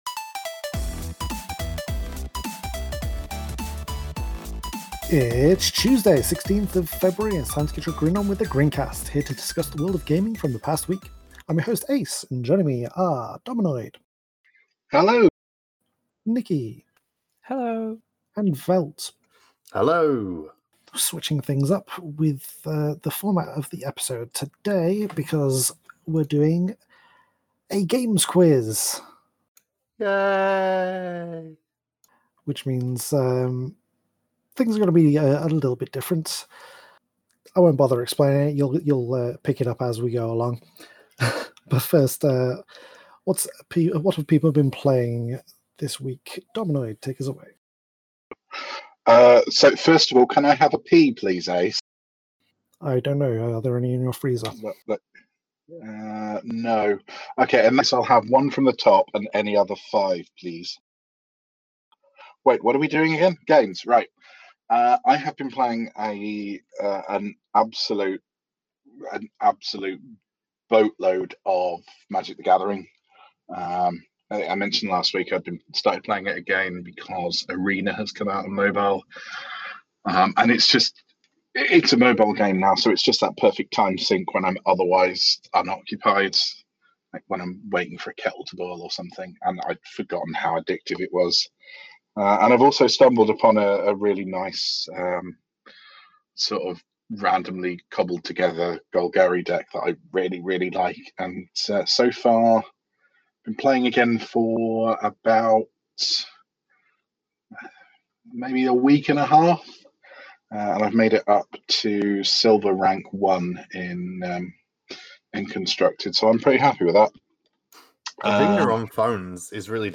This week the cast had a GamesQuiz!